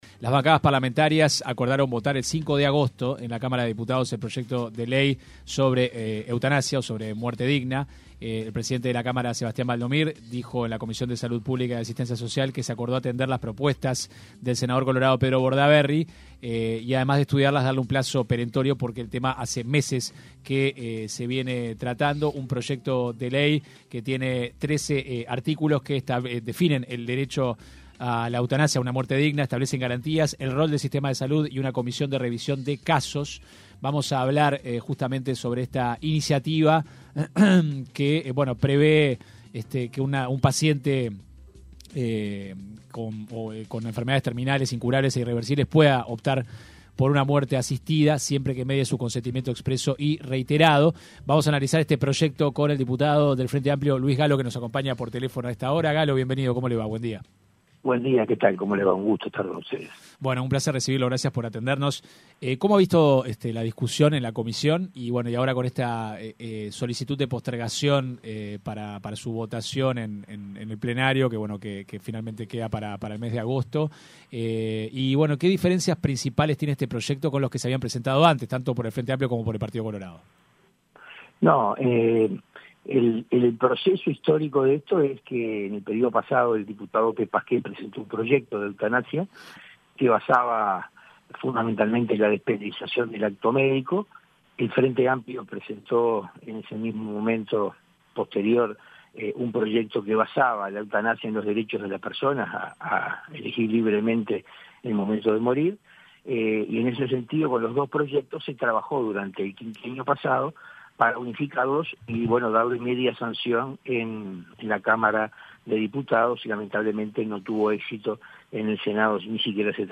Entrevista completa El cambio se debió al llamado del senador colorado Pedro Bordaberry a la presidenta de la Comisión de Salud de Diputados, Nibia Reisch.
Gallo explicó en entrevista con 970 Noticias cuanto demora en finalizar el trámite para que le sea otorgada la eutanasia al paciente. El legislador también explicó que en cualquier momento del proceso el solicitante puede cambiar de opinión.